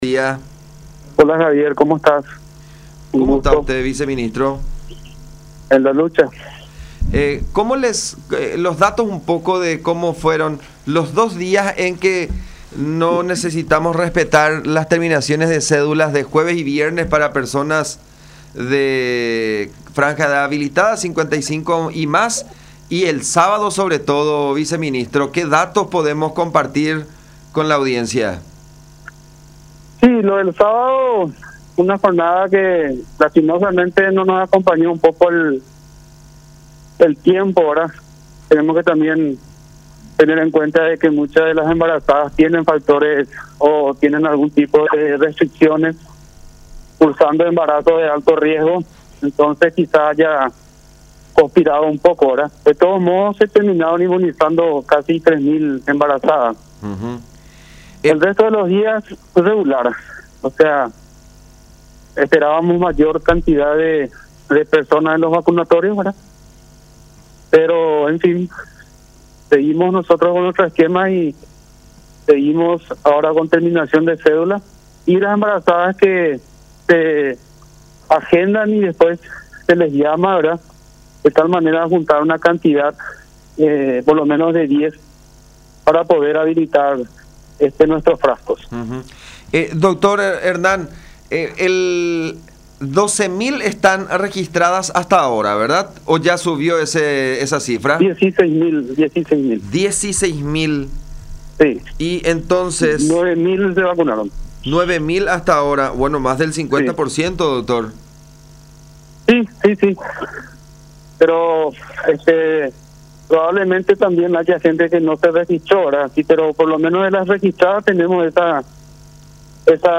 Eso ya se está evaluando para esta semana”, dijo Martínez en conversación con Todas Las Voces por La Unión.